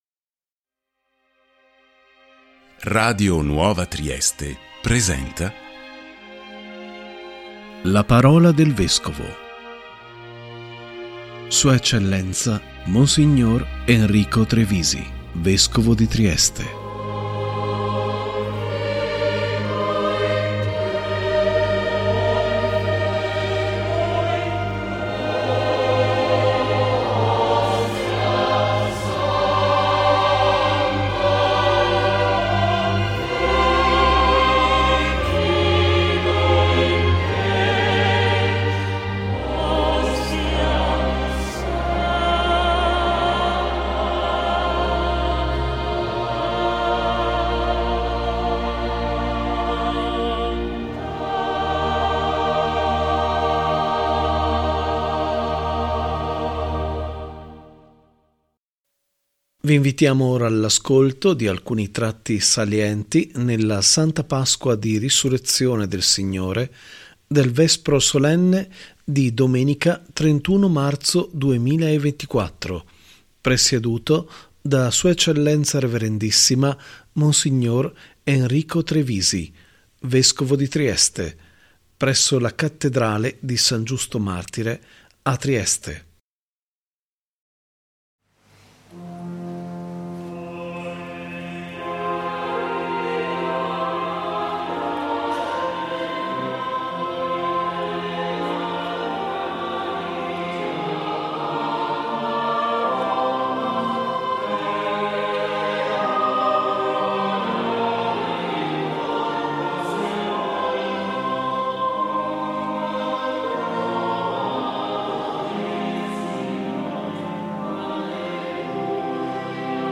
♦ si è tenuta, nella Santa Pasqua di Risurrezione del Signore, il Vespro Solenne di Domenica 31 marzo 2024 presieduto da S.E. Rev.issima Mons. Enrico Trevisi, Vescovo di Trieste presso la Cattedrale di San Giusto martire a Trieste